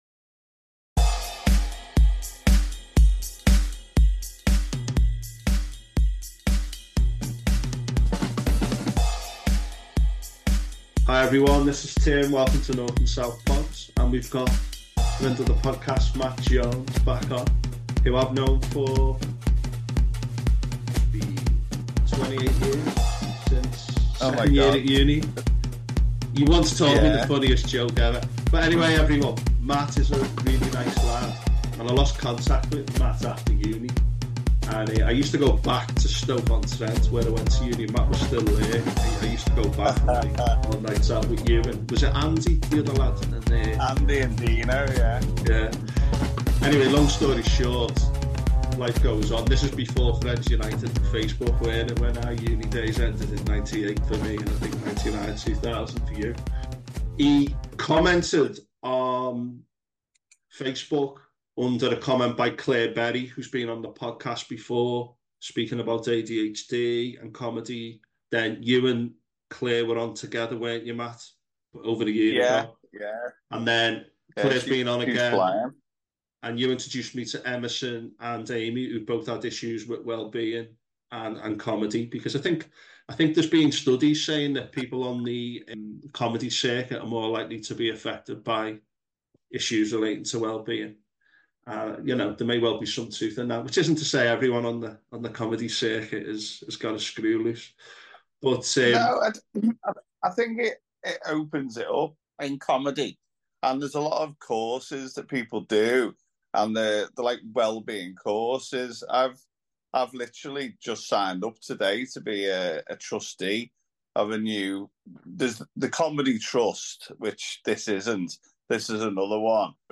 Warning – There is the odd swear word in this podcast.